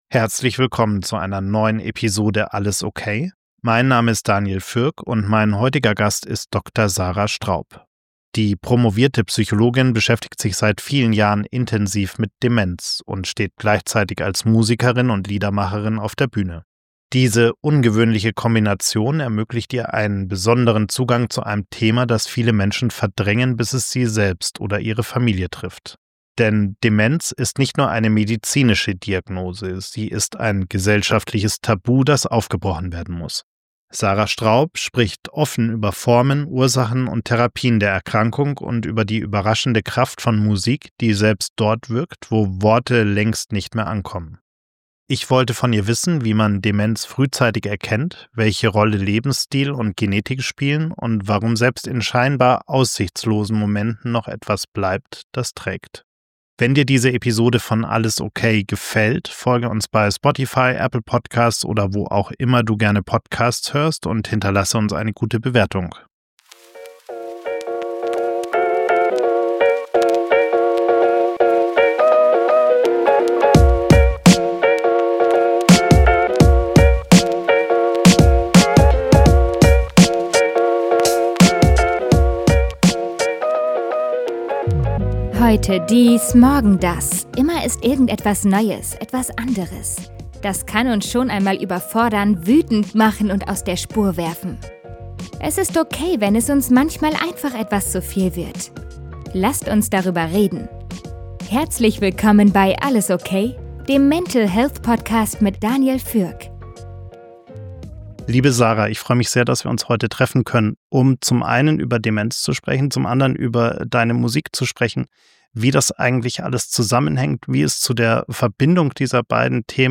Ein Gespräch über Aufklärung, Hoffnung und die Kraft der Erinnerung.